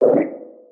Sfx_creature_penguin_waddle_voice_06.ogg